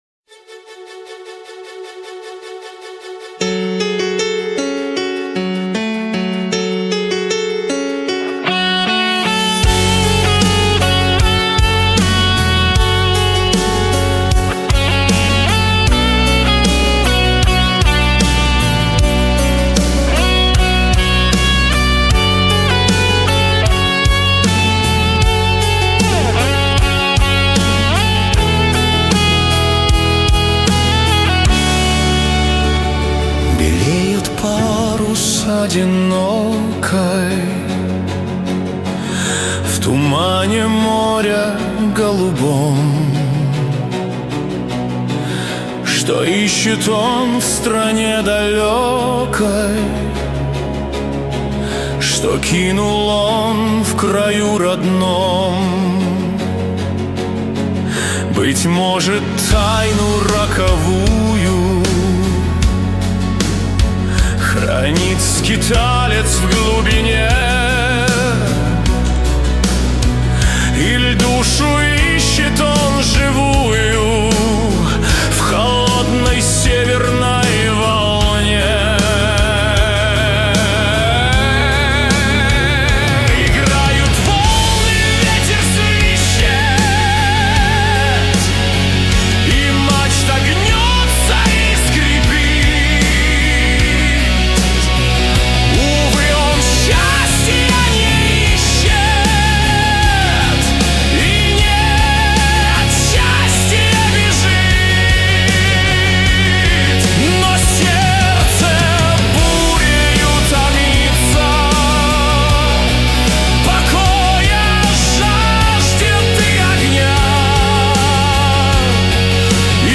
Эпическая Рок Баллада